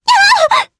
Selene-Vox_Damage_jp_01.wav